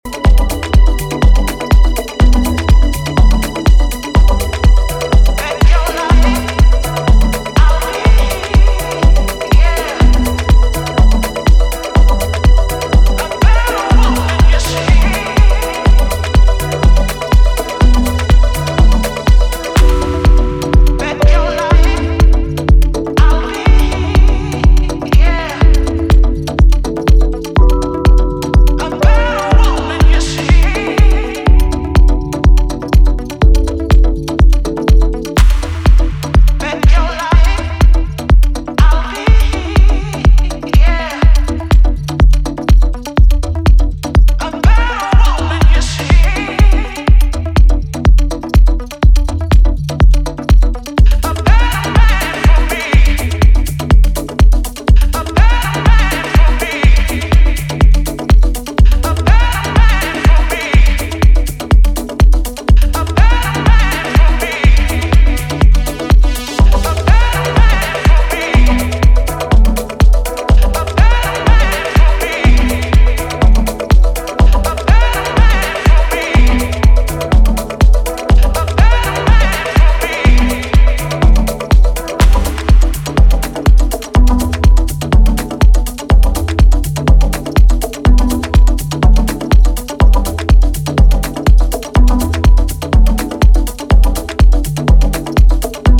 future house classic